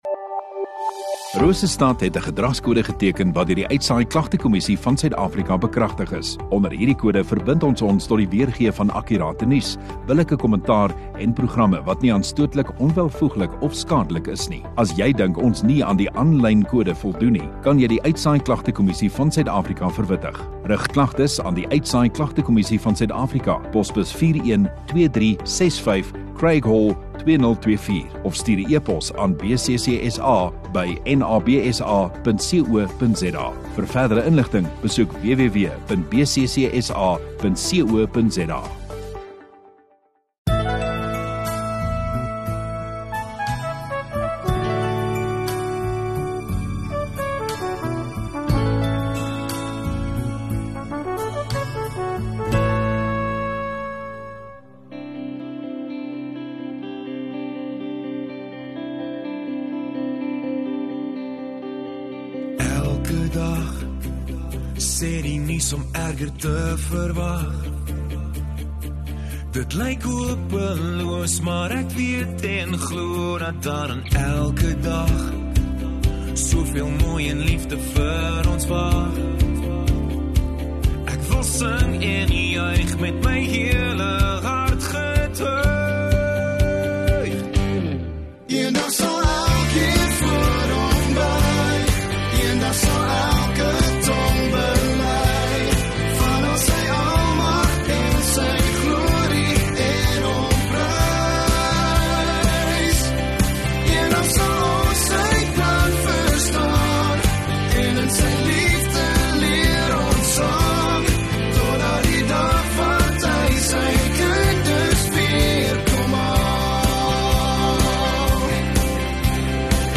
10 Aug Saterdag Oggenddiens